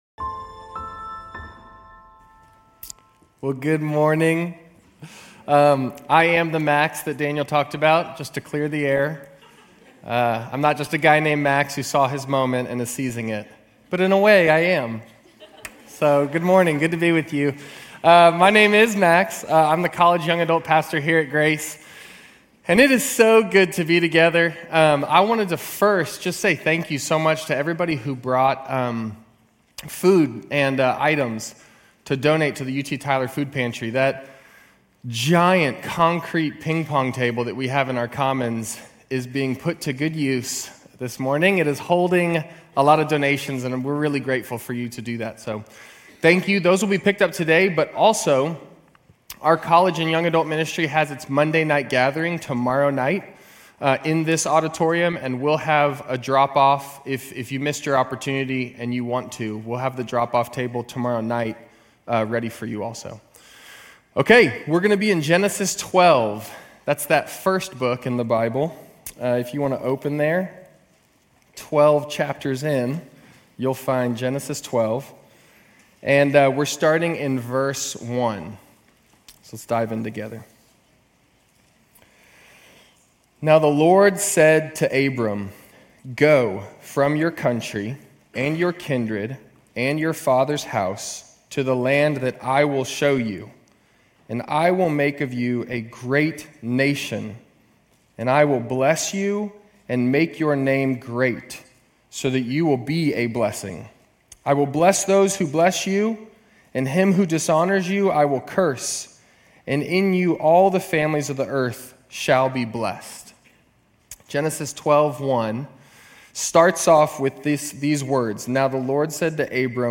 Grace Community Church University Blvd Campus Sermons Genesis 12:1-3,15,17 - Abraham Oct 14 2024 | 00:36:39 1x 00:00 / 00:36:39 Subscribe Share RSS Feed Share Link Embed